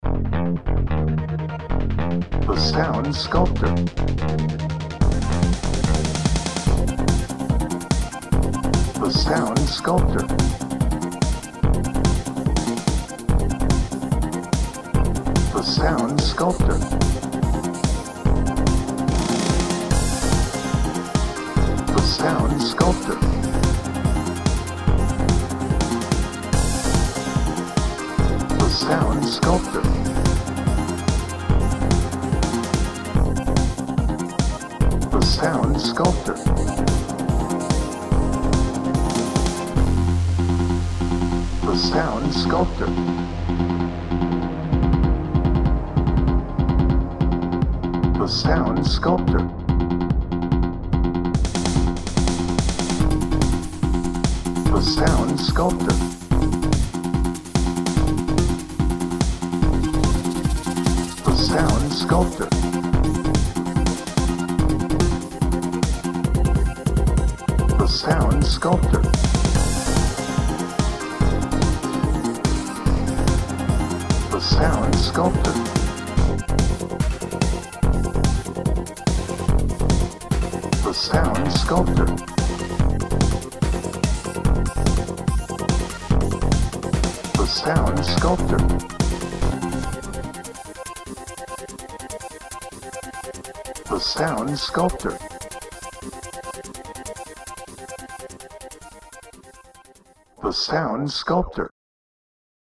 Chase
Edgy
Electronic
Speed